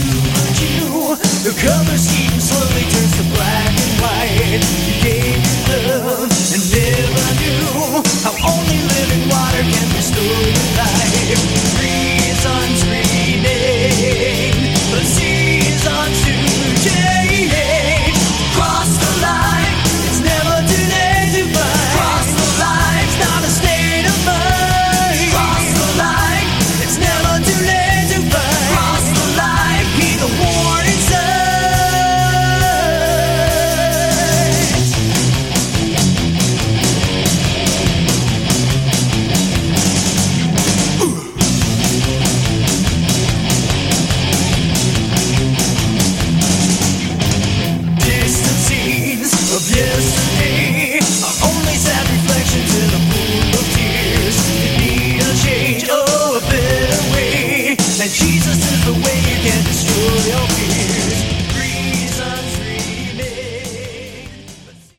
Category: Christian Hard Rock